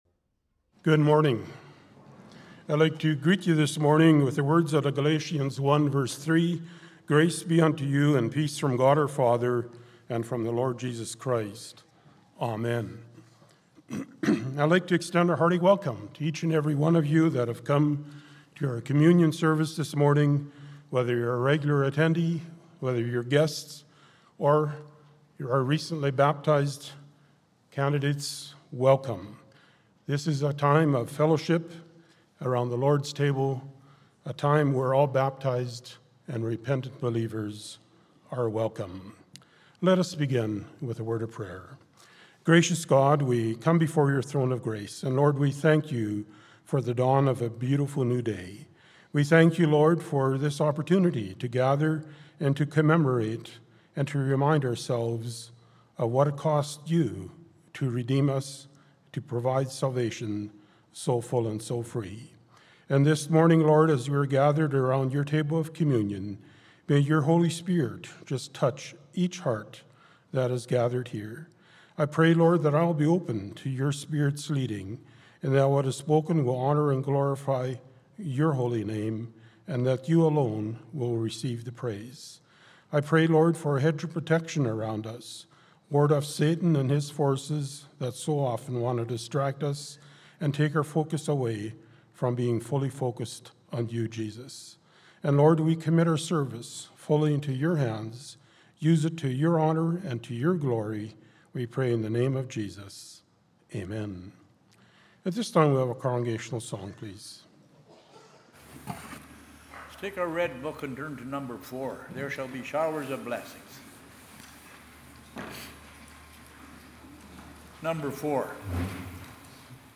Communion Service